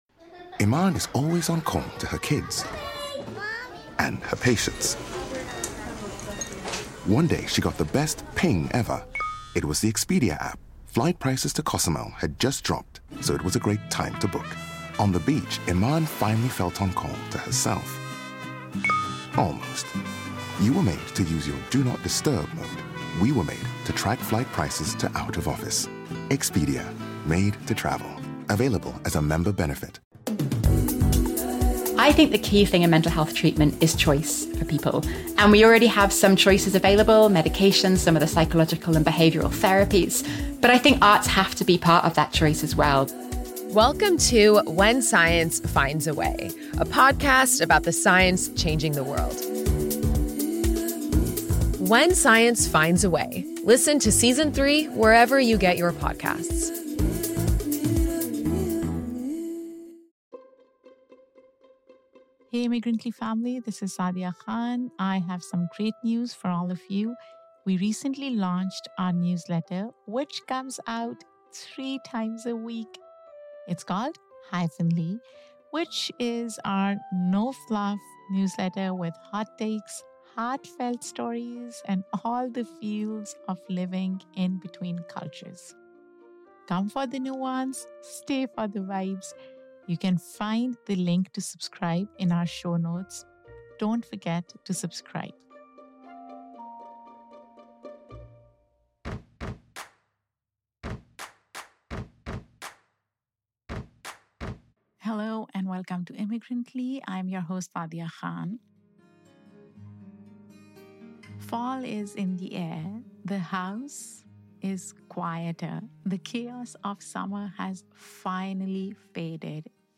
In this solo episode of Immigrantly